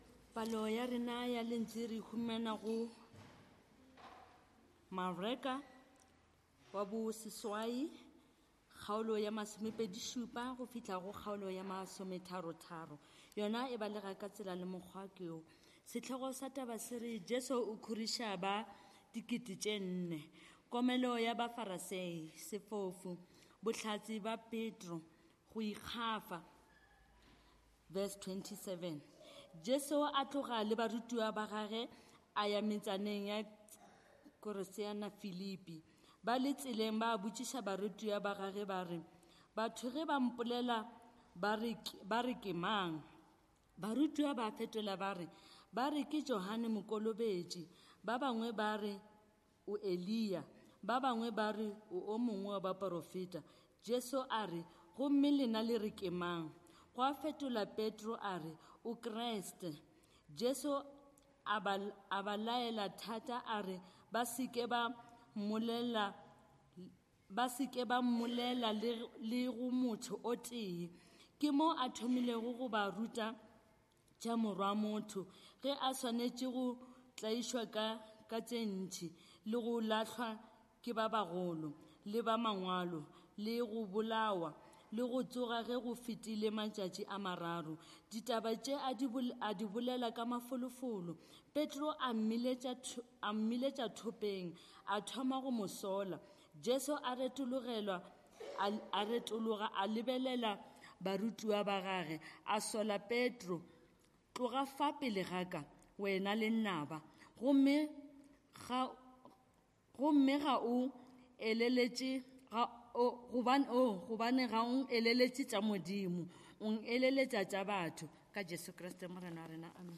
Kopanang Service from Trinity Methodist Church, Linden, Johannesburg
Trinity Methodist Church Sermons The Crucified God - Who Do You Say That I Am?